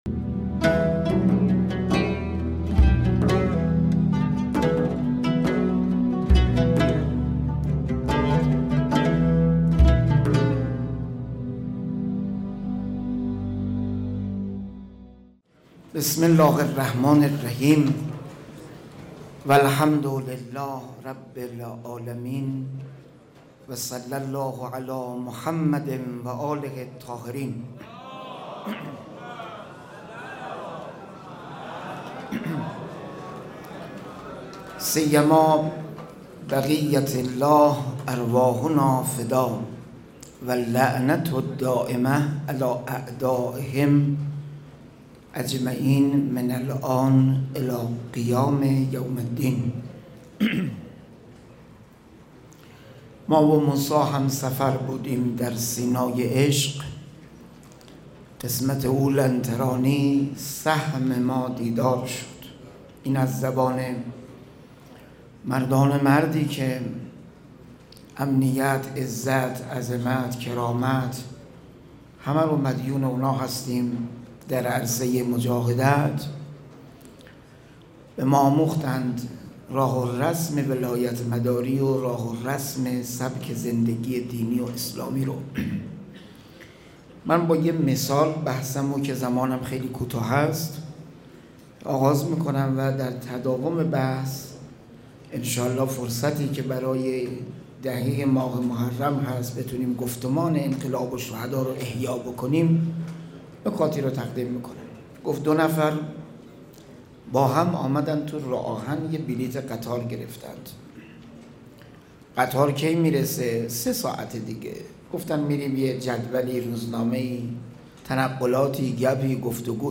نهمین همایش هیأت‌های محوری و برگزیده کشور | شهر مقدس قم - مسجد مقدس جمکران - مجتمع یاوران مهدی (عج)